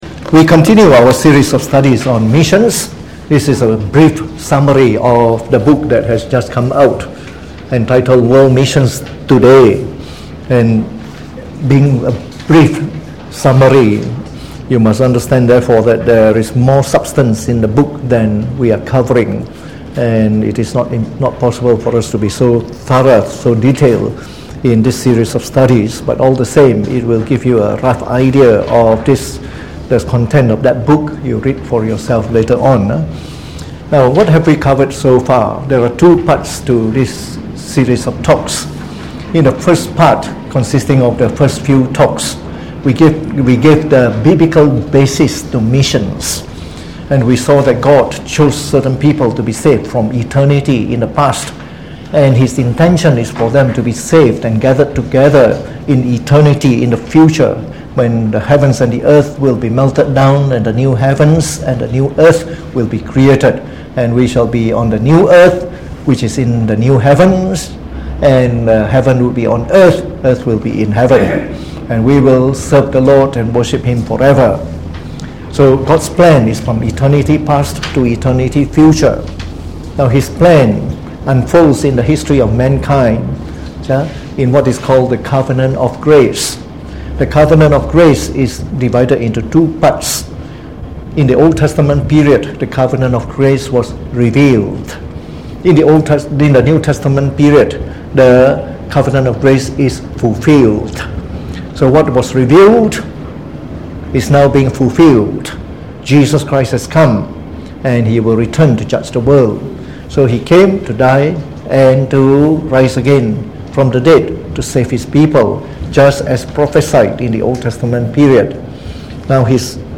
Preached on the 13th of February 2019 during the Bible Study, from our series on Missions.